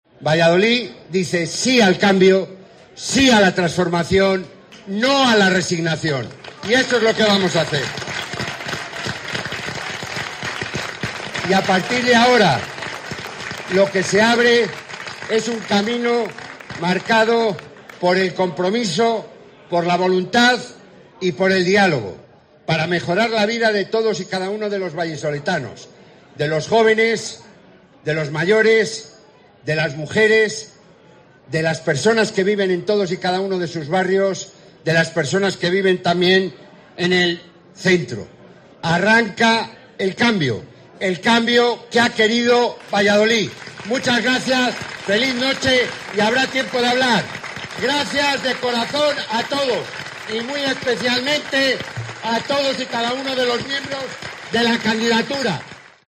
Ante el clamor de los afiliados y simpatizantes, que lo han recibido al grito de "¡Alcalde!", Carnero ha asegurado que "arranca el cambio que ha querido Valladolid".